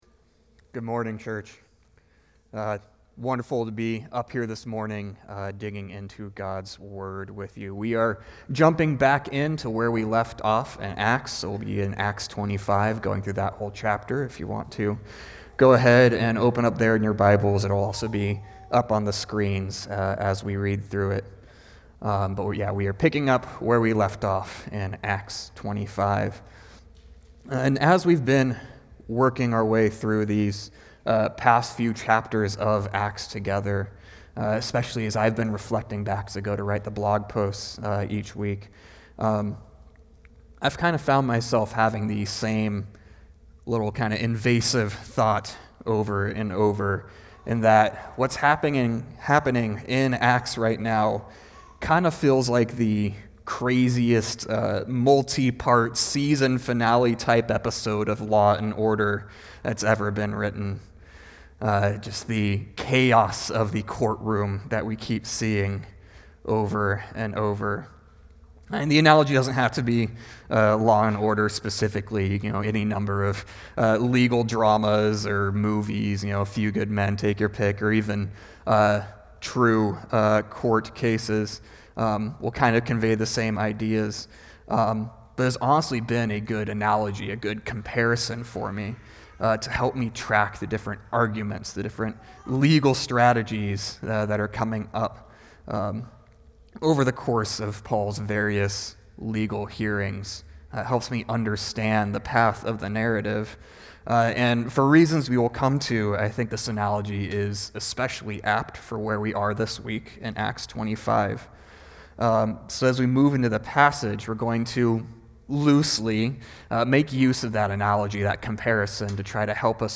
SERMON | Acts 25:1-27 | Paul Appeals to Caesar | Light in the Desert Church